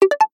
notification_sounds
zaping.mp3